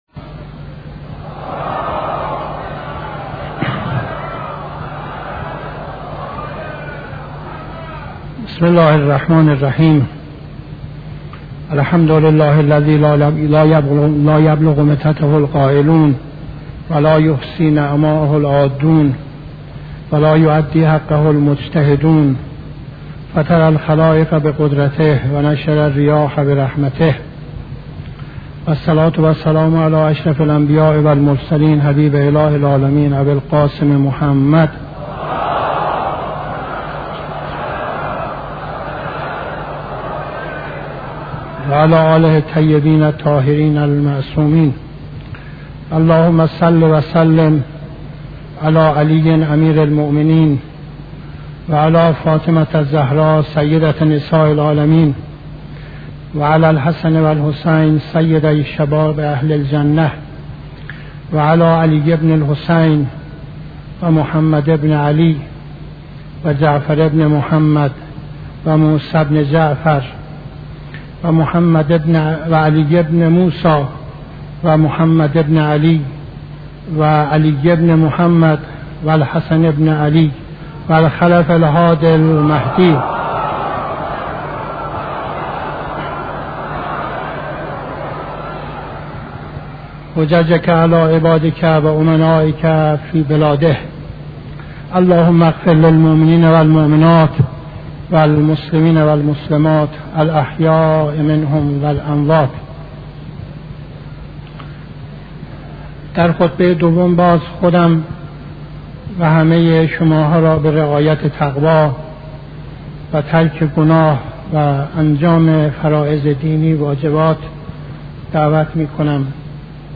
خطبه دوم نماز جمعه 24-07-71